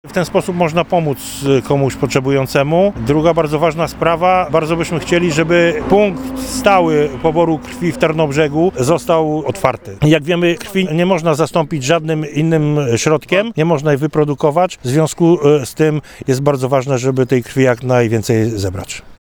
Rejestracja osób chętnych do udziału w akcji prowadzona będzie w godzinach od 9 do 13 – mówi pomysłodawca przedsięwzięcia Waldemar Stępień.